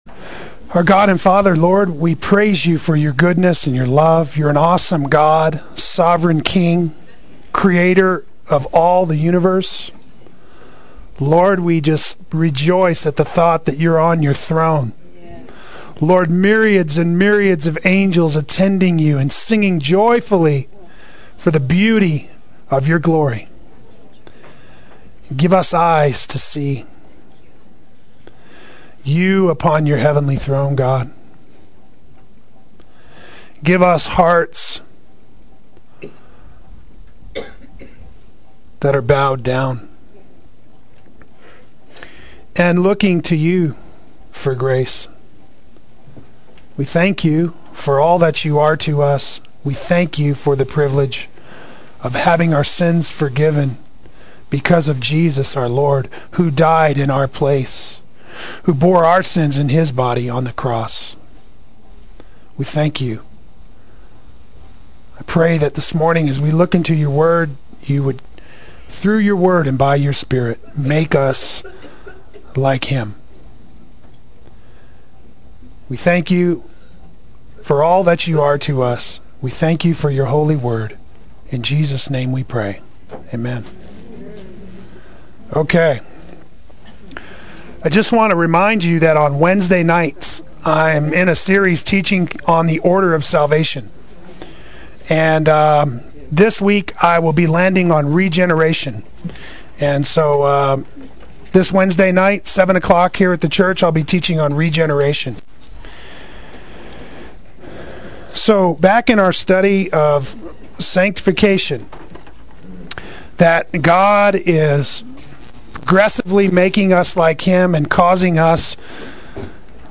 Play Sermon Get HCF Teaching Automatically.
Pursuing Virtue Adult Sunday School